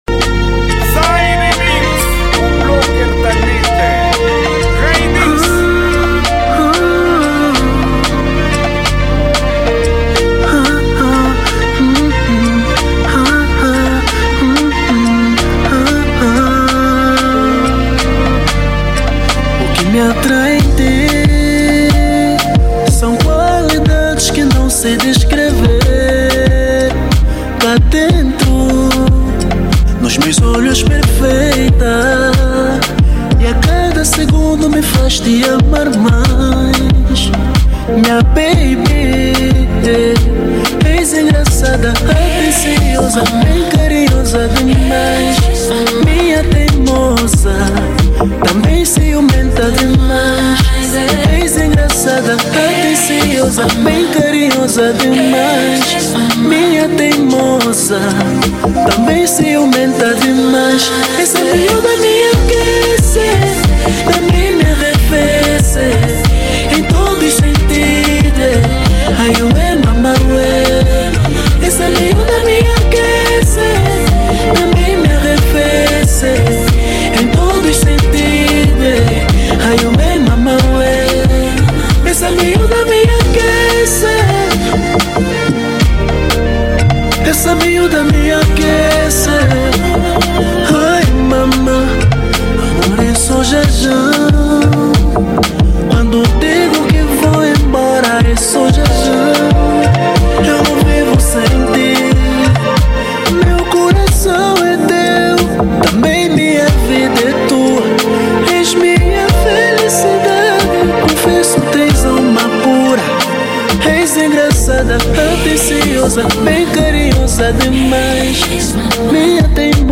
zouk